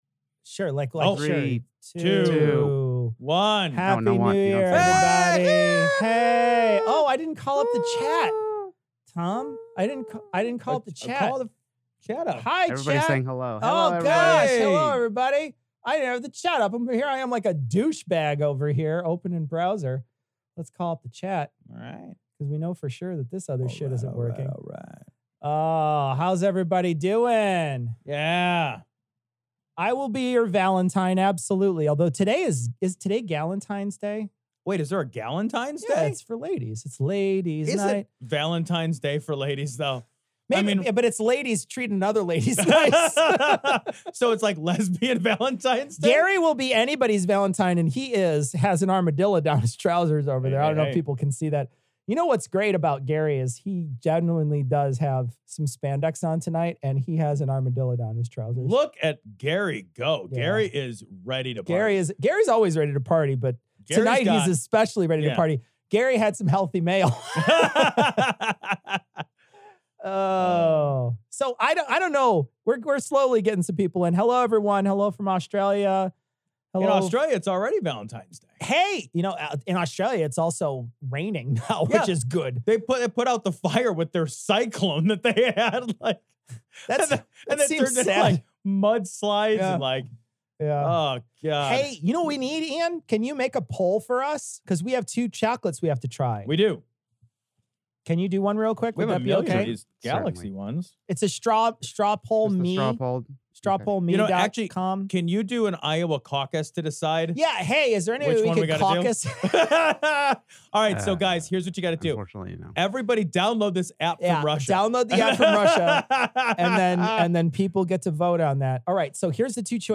Audio from our livestream last thursday.